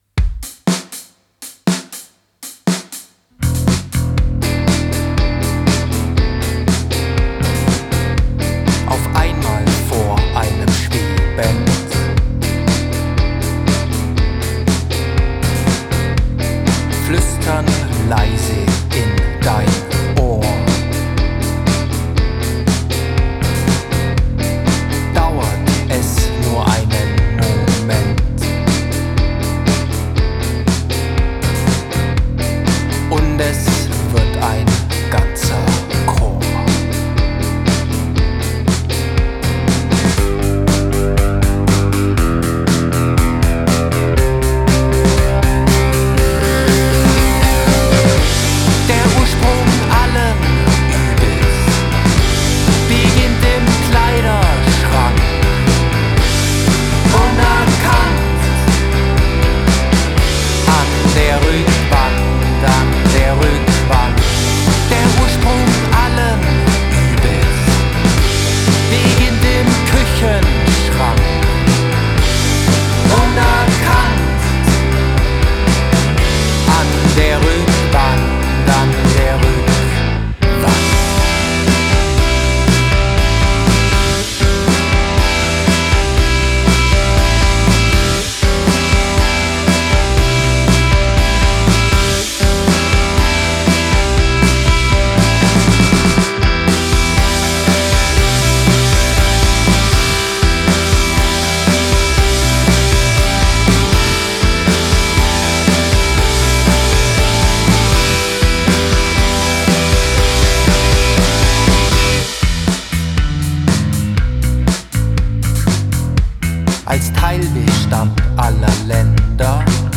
Gitarre
Bass
Schlagzeug
Es wird wird laut, verrückt, schrammlig.